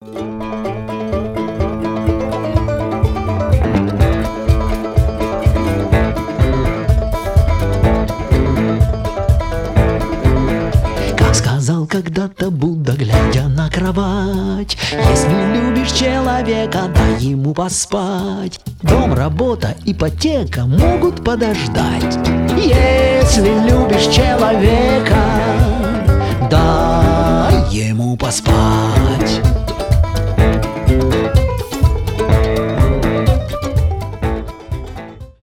кантри
банджо
фолк